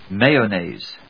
音節may・on・naise 発音記号・読み方
/méɪənèɪz(米国英語), ˈmeɪʌˌneɪz(英国英語)/